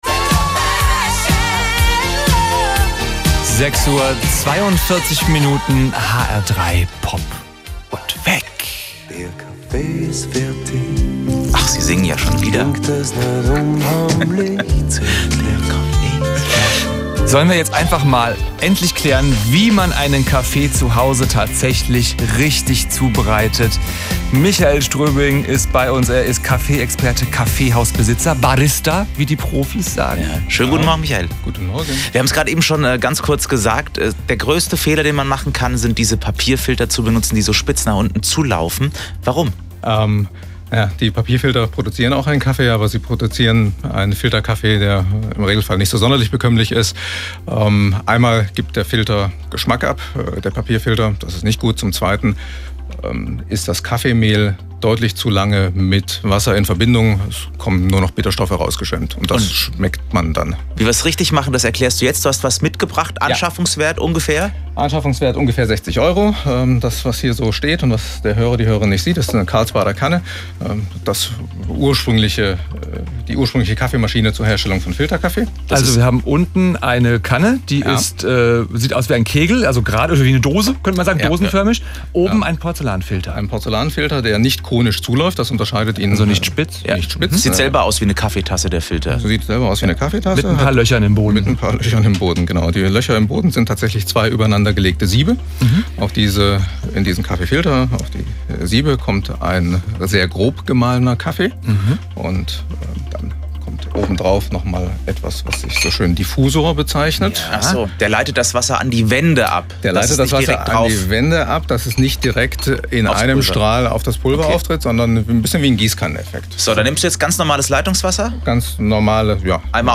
Wir sind heute morgen mit zwei unserer Karlsbader Kannen ins Funkhaus umgezogen und haben in hr3 “Pop&Weck” ein wenig Kaffeebrühen zelebriert.
Hier die Mitschnitte: